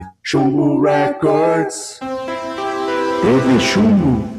Cronometro De 5 Segundos Sound Button - Free Download & Play